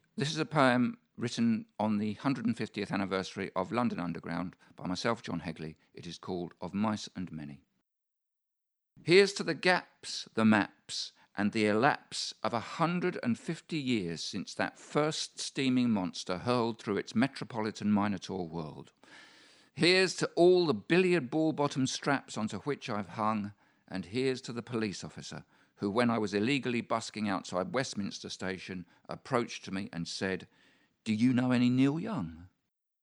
Thankyou London Underground read by John Hegley